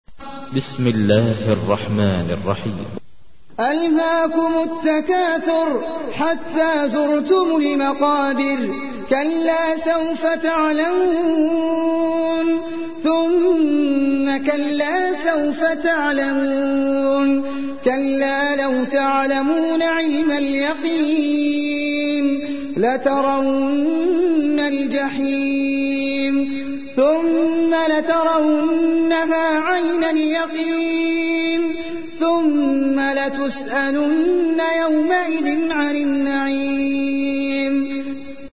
تحميل : 102. سورة التكاثر / القارئ احمد العجمي / القرآن الكريم / موقع يا حسين